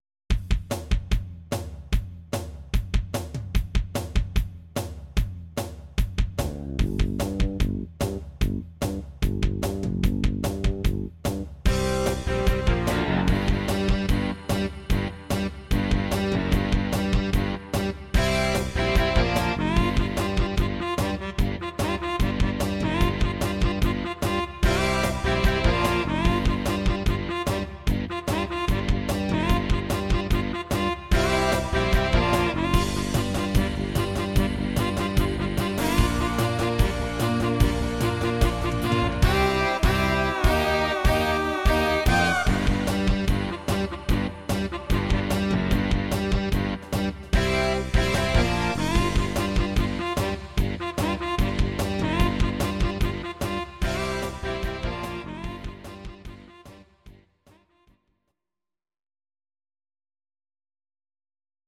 Audio Recordings based on Midi-files
Our Suggestions, Pop, 1970s